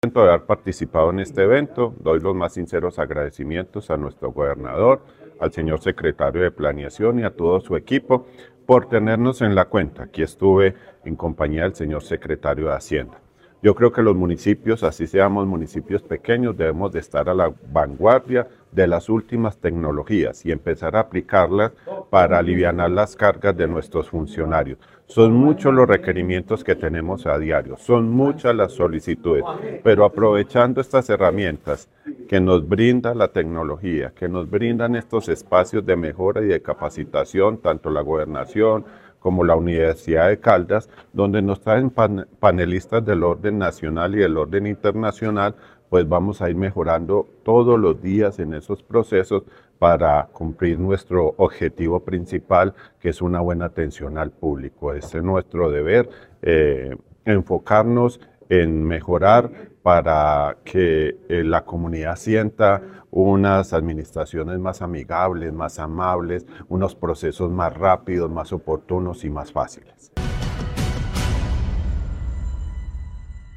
La Secretaría de Planeación de Caldas, en articulación con la Universidad de Caldas y el proyecto Talento Tech, lideró el Foro Departamental “Gobiernos Caldenses en la Revolución Digital”, un espacio orientado a analizar los retos y oportunidades que ofrece la inteligencia artificial (IA) para el desarrollo territorial.
Héctor Mauricio Torres Álvarez, Alcalde de Supía.